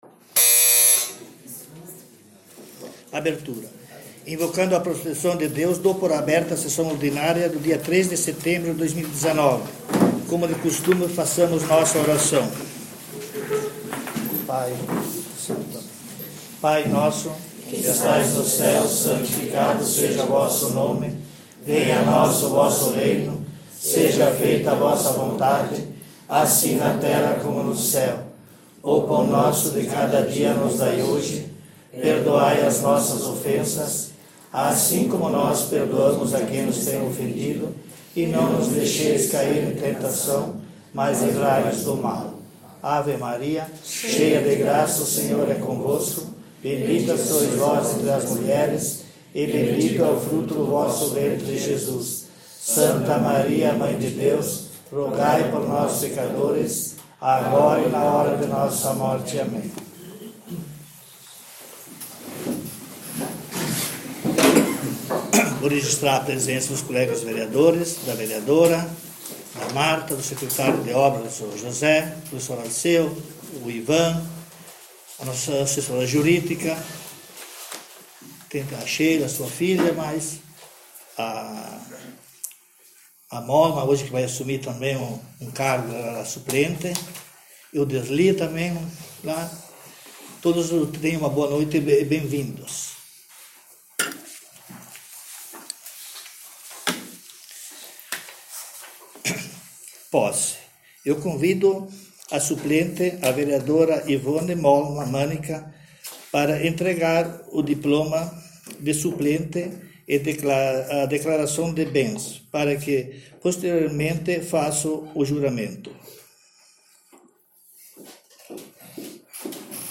SESSÃO ORDINÁRIA 03/09/2019